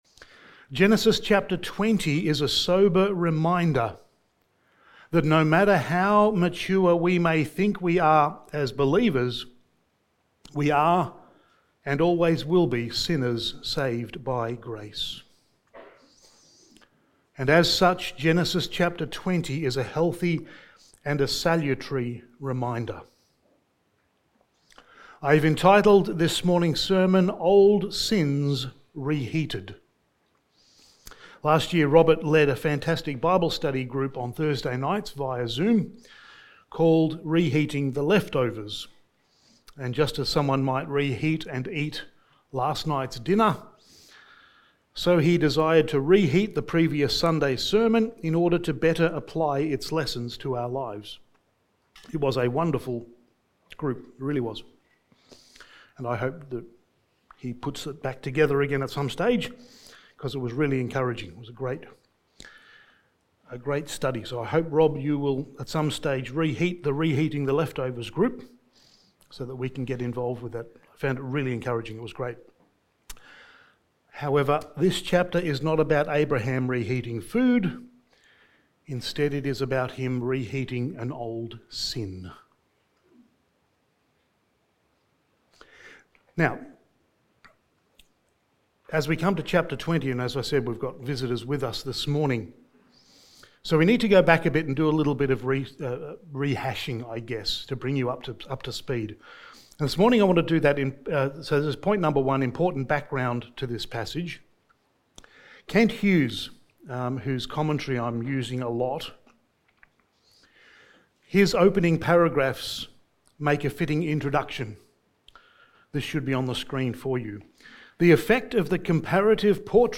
Sermon
Genesis 20:1-18 Service Type: Sunday Morning Sermon 32 « The Tragedy of a Wasted Life Part 3 of 3